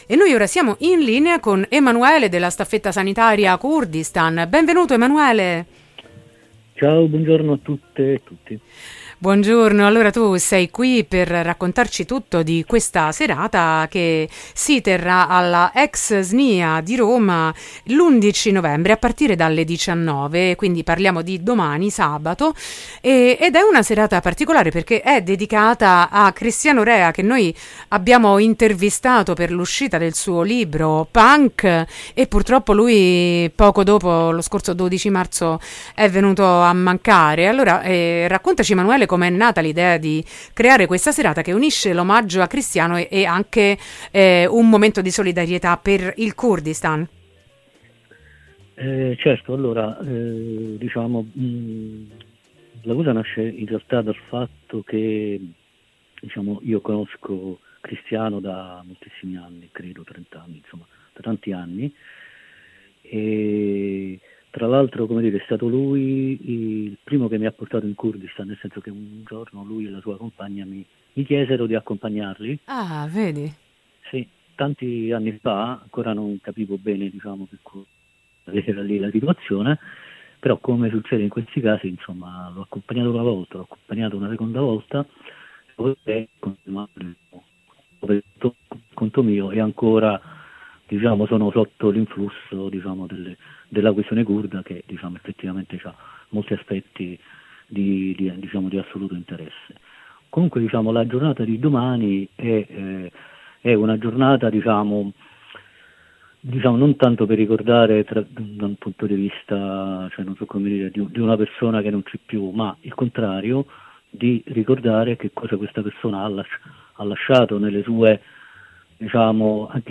intervista-pankurdistan.mp3